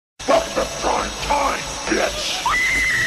Play, download and share welcome to prime time original sound button!!!!